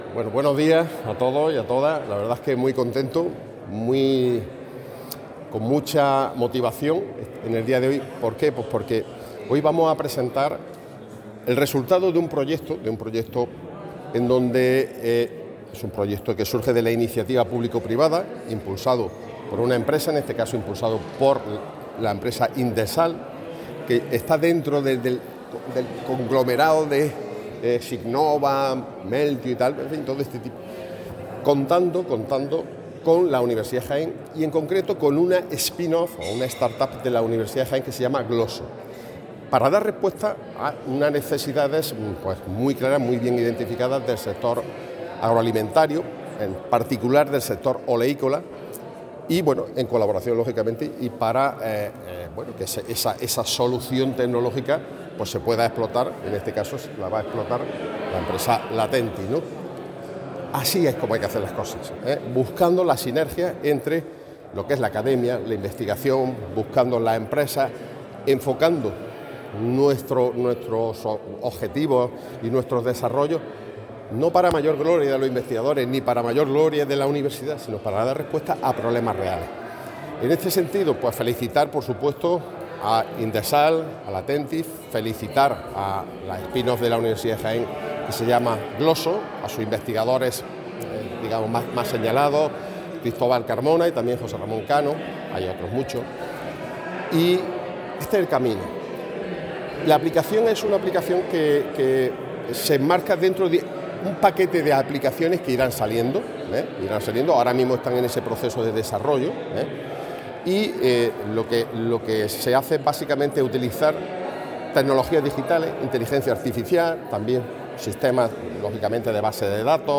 declaraciones_Rector_app_BioGrow_Latentis.mp3